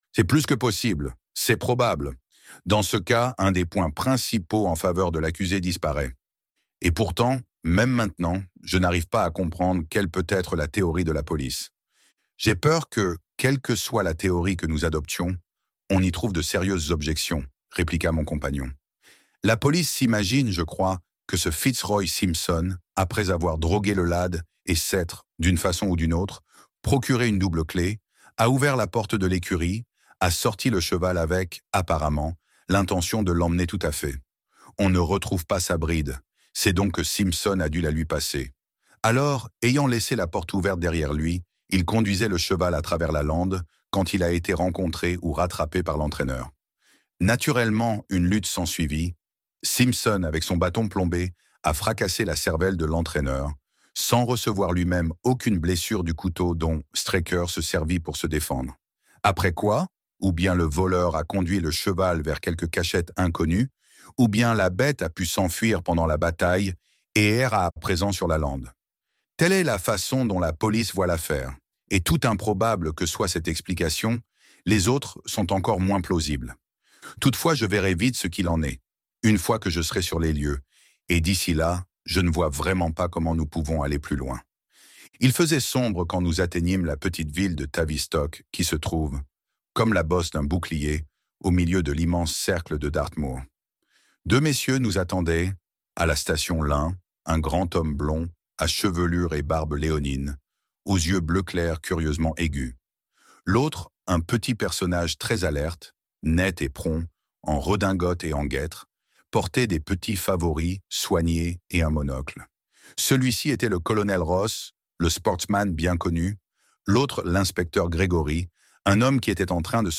Les Mémoires de Sherlock Holmes - Livre Audio